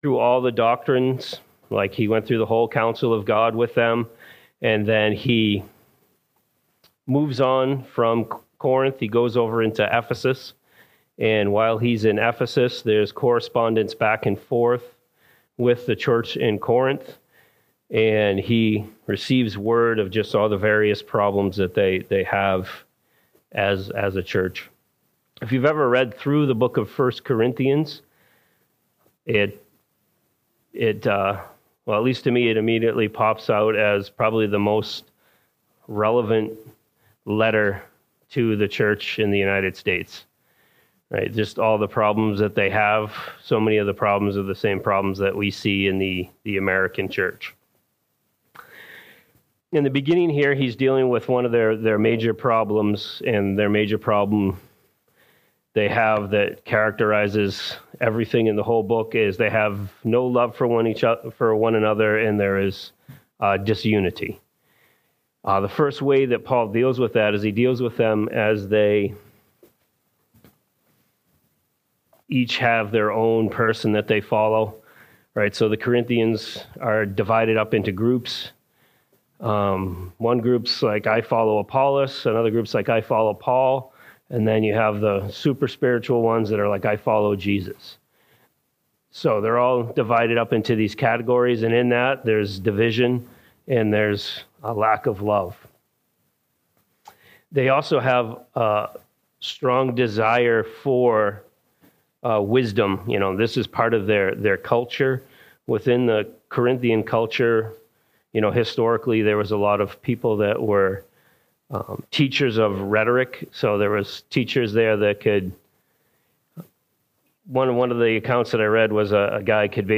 Latest Sermons